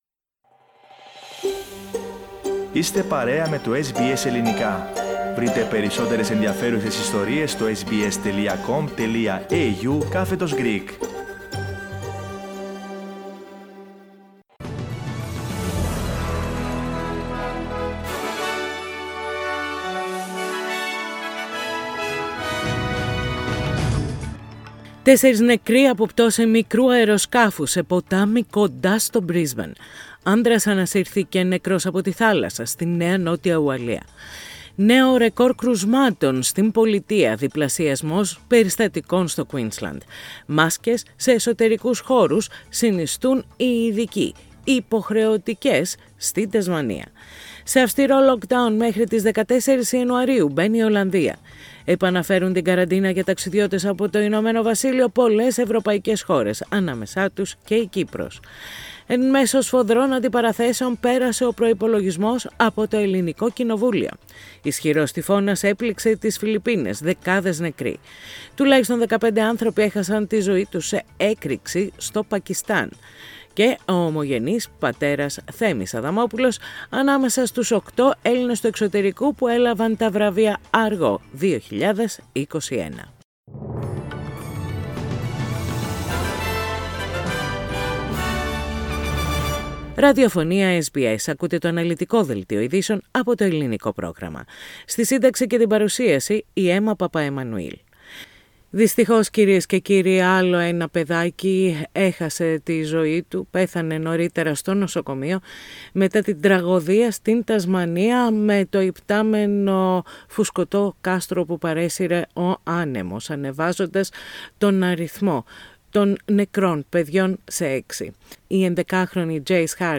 Ειδήσεις στα Ελληνικά - Κυριακή 19.12.21
News in Greek. Source: SBS Radio